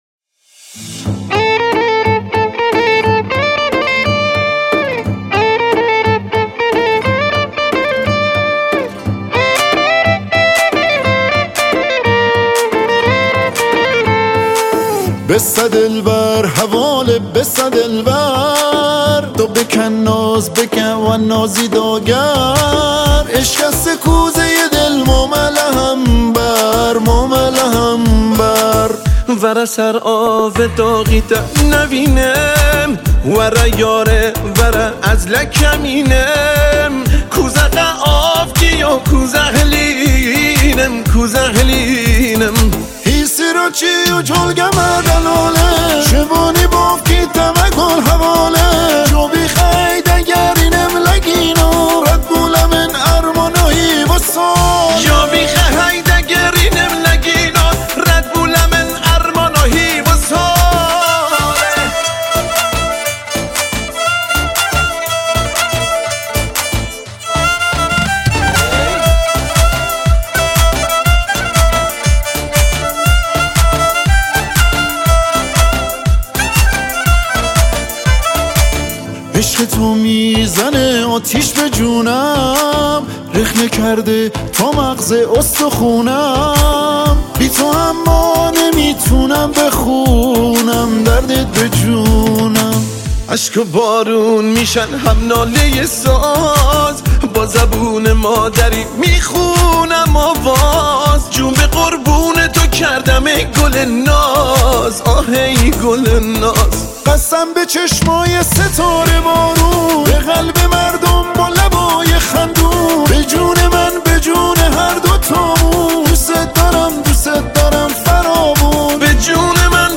کرمانجی
آهنگ مشهدی